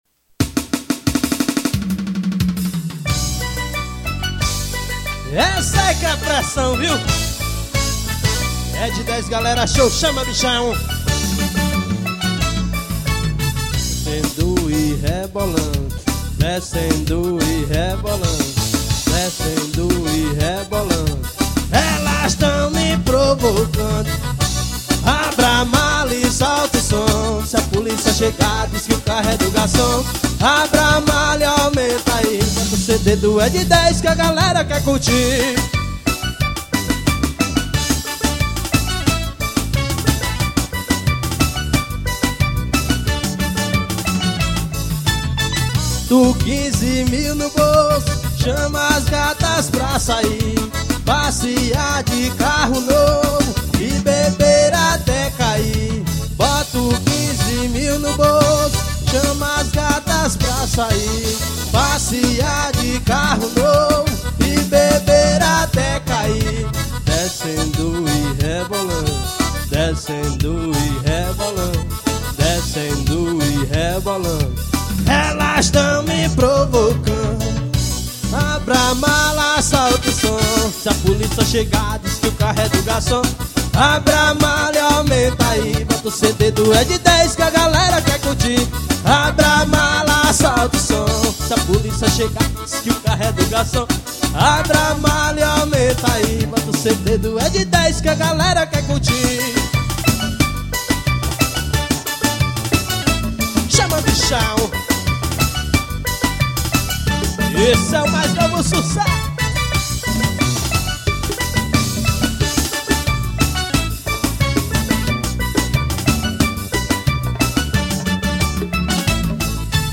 forró estourado.